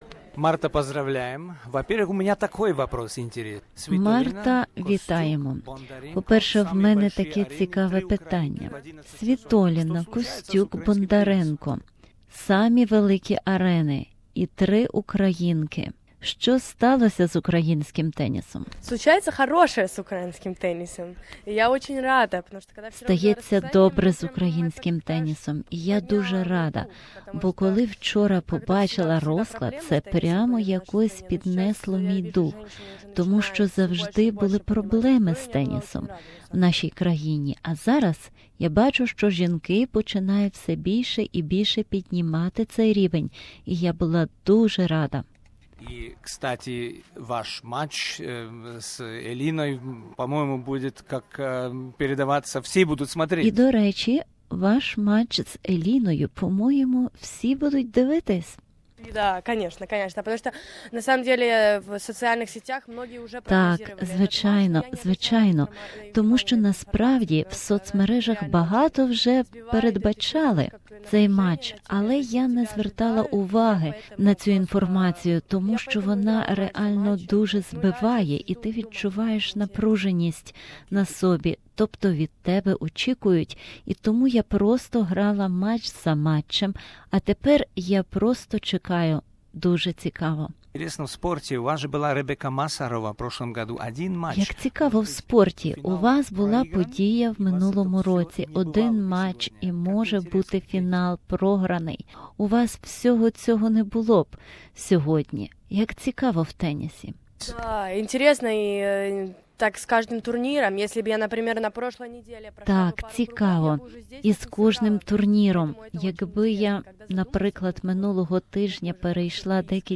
Interview with H Marta Kostyuk